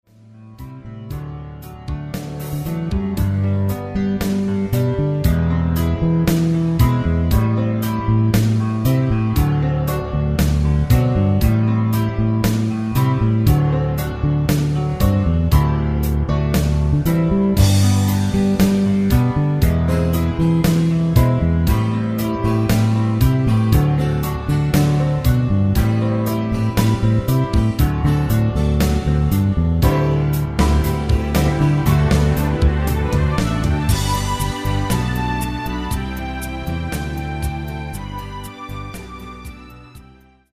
DEMO MP3 MIDI